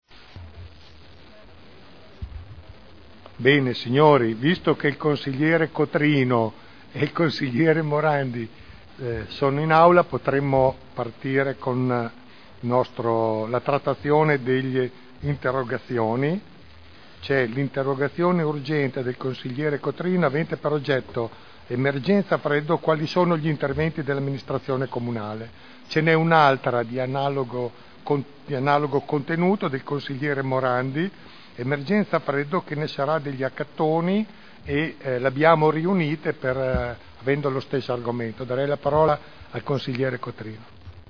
Il Presidente Giancarlo Pellacani apre la seduta per interrogazioni e interpellanze